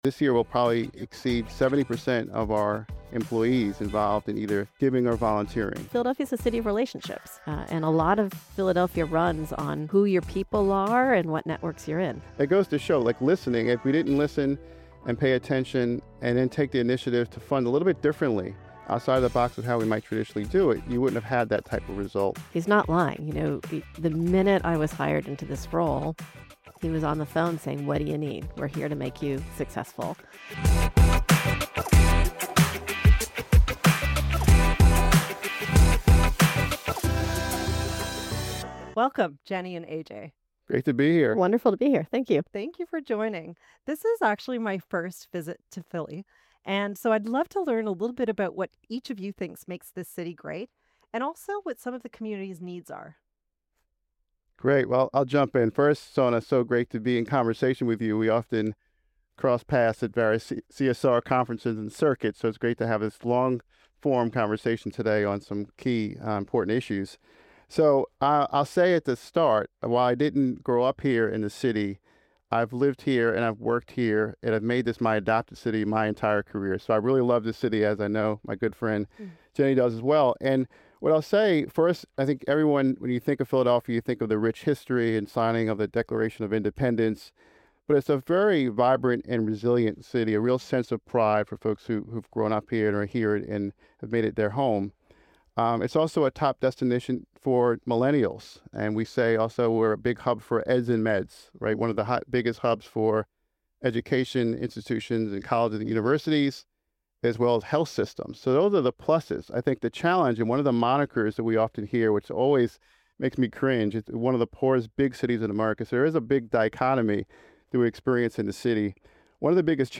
Filmed live in the Vanguard Studio, watch this behind-the-scenes footage of this podcast recording.